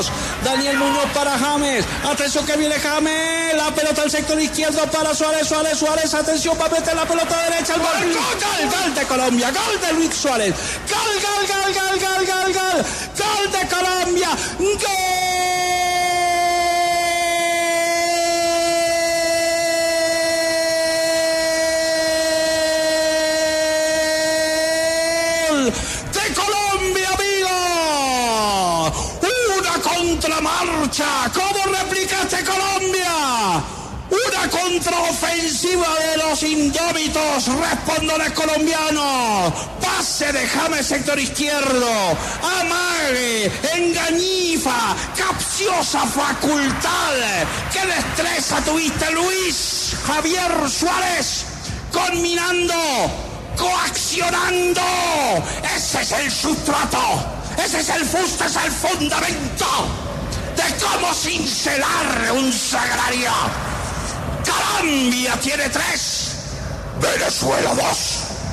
Hernán Peláez y Martín de Francisco narran el partido entre la Selección Colombia y Venezuela.